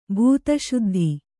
♪ bhūta śuddhi